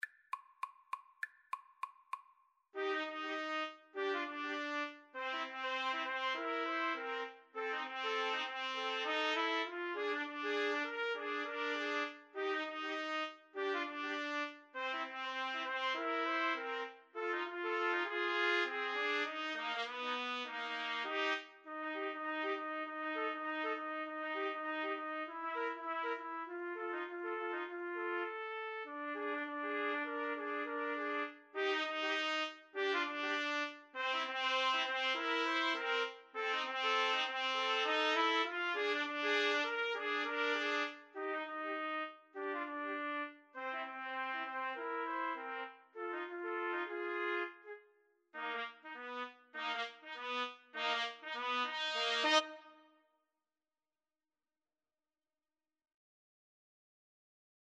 Quick Swing = c. 100
Jazz (View more Jazz 2-Trumpets-Trombone Music)